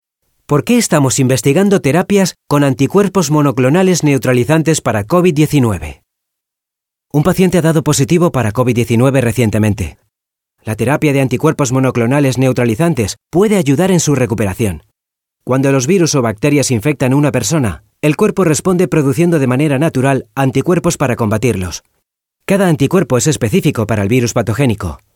Excelente dicción, vocalización y sonido brillante.
Utilizamos micrófonía Neuman U87 y Audio Technica 4050/CM5.
Sprechprobe: Werbung (Muttersprache):